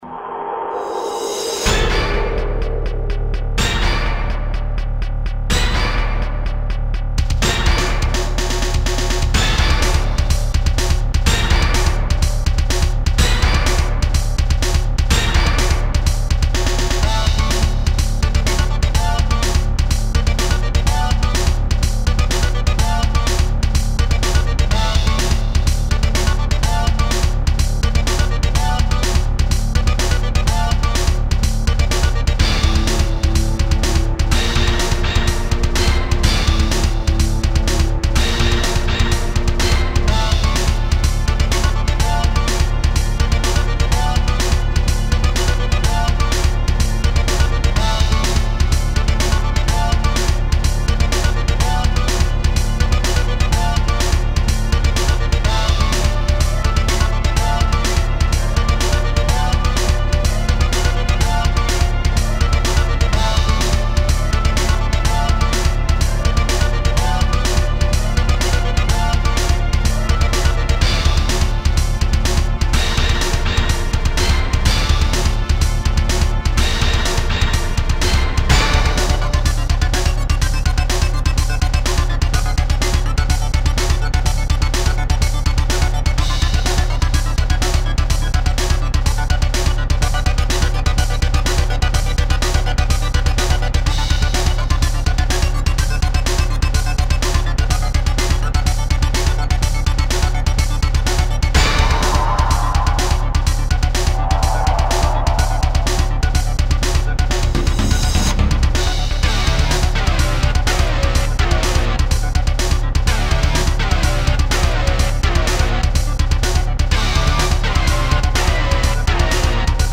1 Recorded sound off of Video Game.